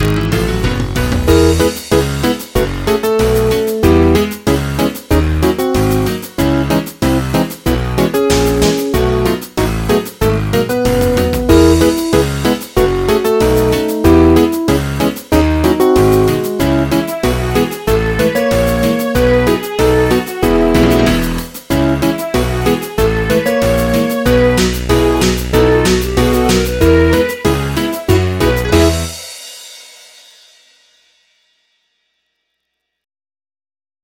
MIDI 6.85 KB MP3